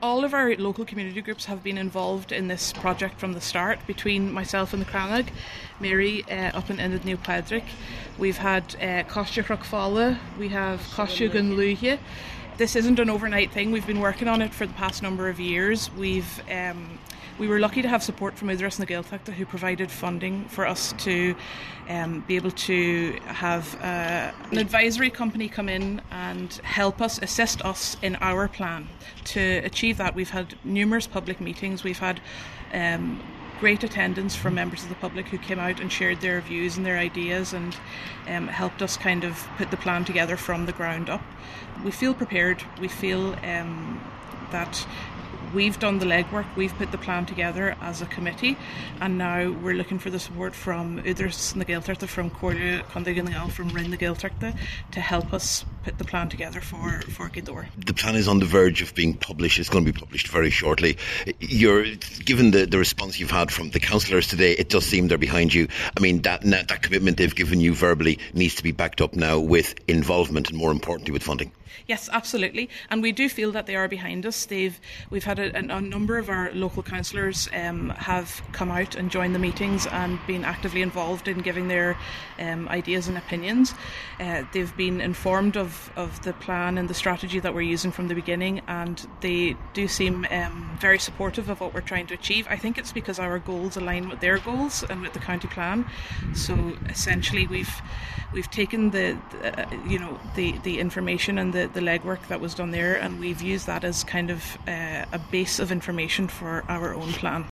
Cathaoirleach Caitríona Nic Garbheith says the council’s support will be vital in implementing the plan: